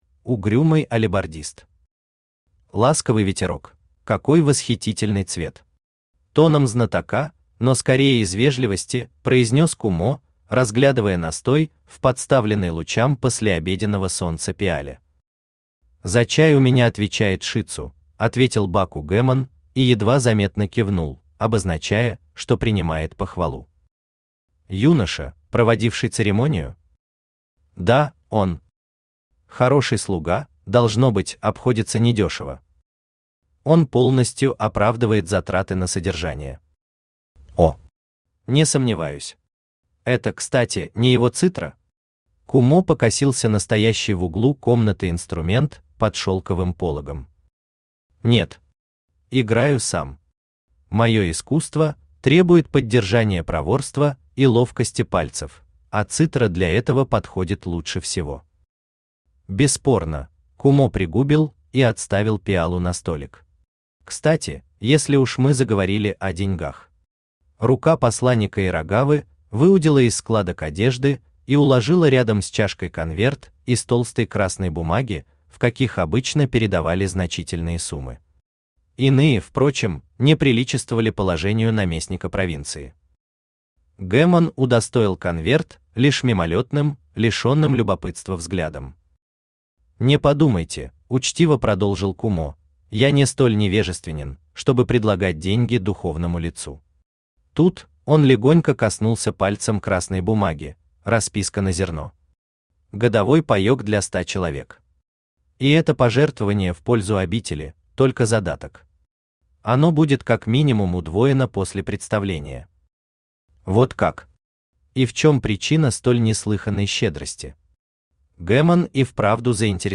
Аудиокнига Ласковый ветерок | Библиотека аудиокниг
Aудиокнига Ласковый ветерок Автор Угрюмый Алебардист Читает аудиокнигу Авточтец ЛитРес.